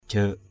/ʥə:ʔ/ 1.